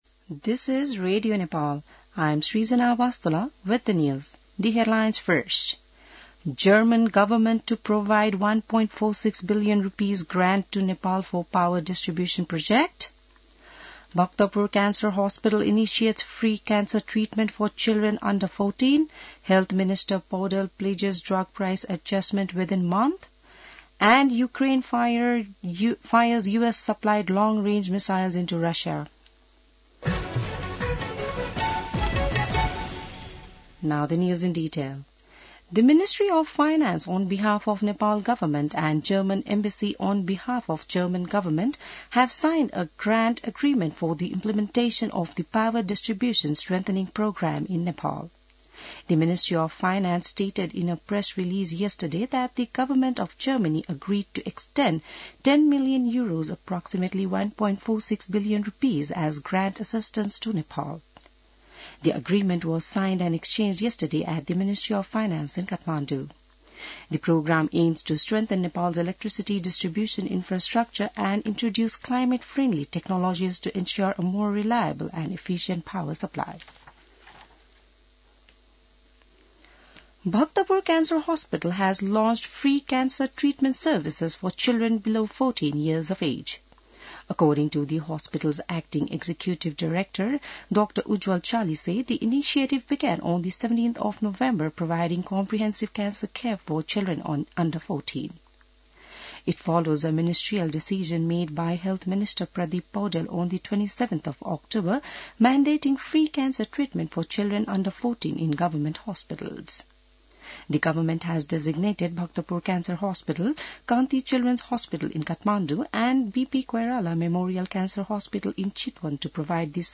बिहान ८ बजेको अङ्ग्रेजी समाचार : ६ मंसिर , २०८१